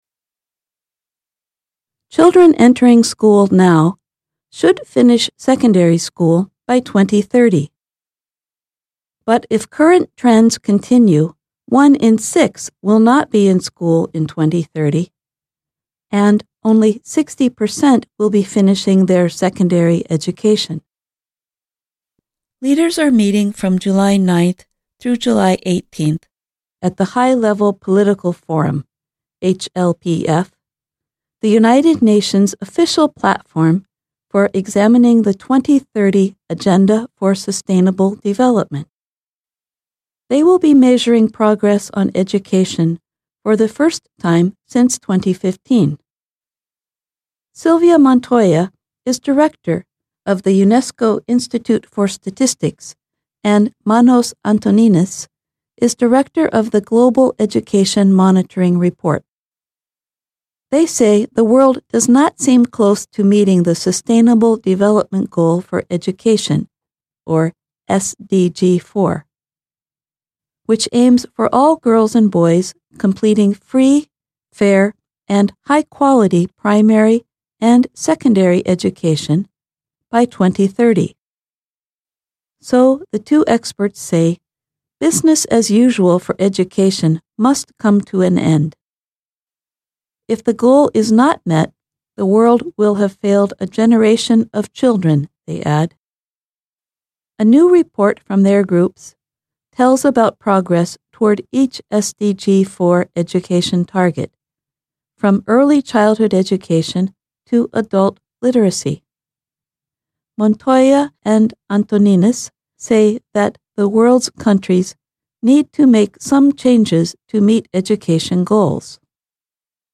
慢速英语:报告呼吁国际社会实现教育目标